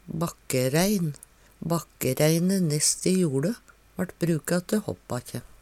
bakkerein - Numedalsmål (en-US)